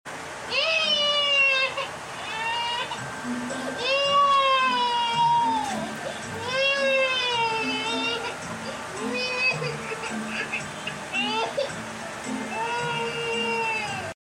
Baby Hazel cries and throws sound effects free download
Baby Hazel cries and throws toy